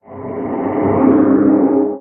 guardian_idle4.ogg